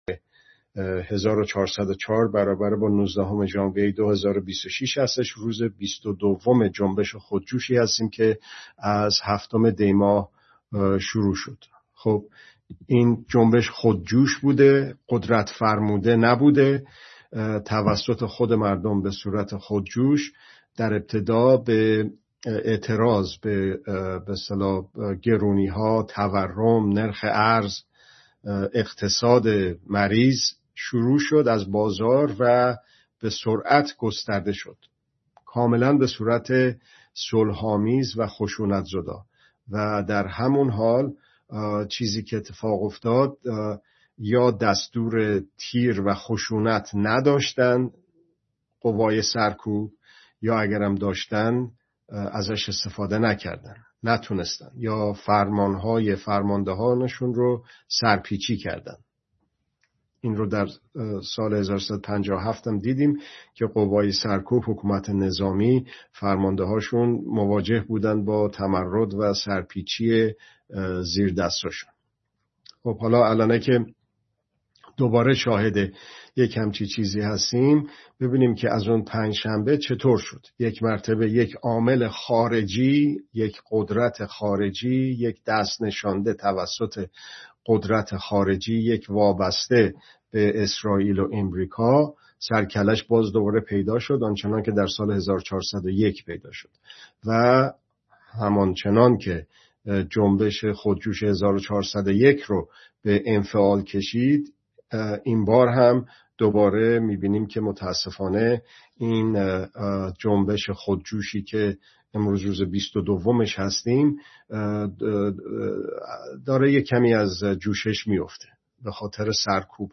گزارش، بررسی، و ارزیابی رویدادها ❊ ( دوشنبه‌ها ساعت۲۱ به‌وقت ایران به‌صورت زنده